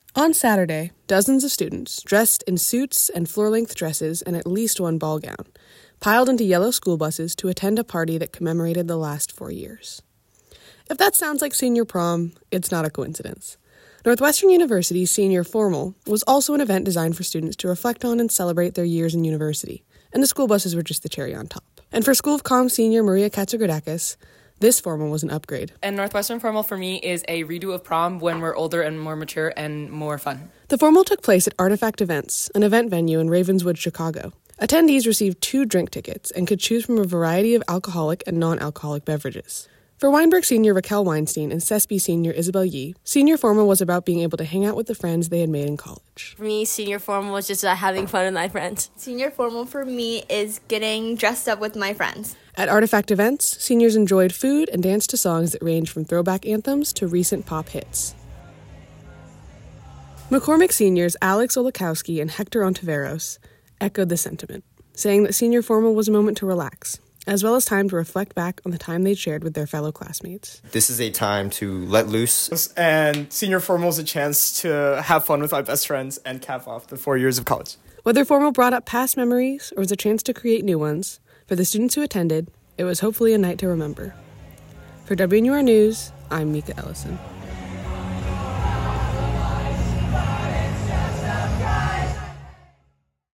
Music: Video of senior formal